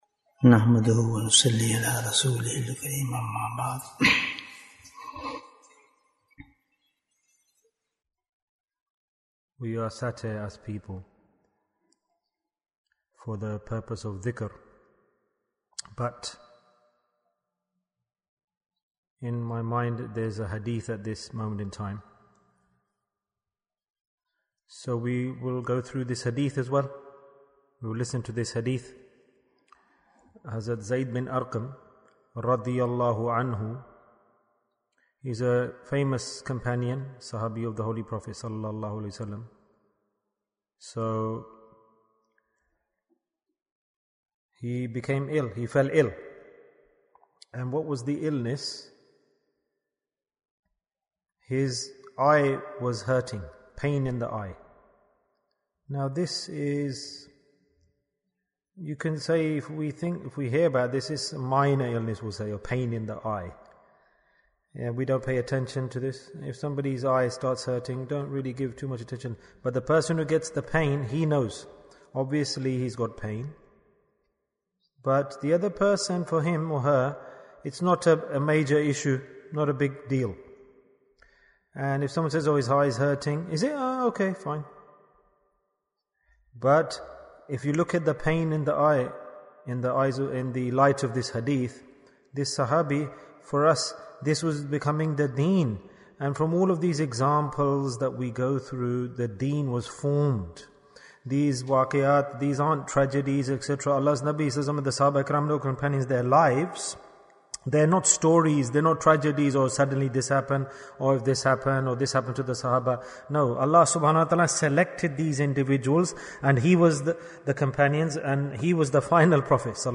Short Advice Bayan, 22 minutes17th January, 2023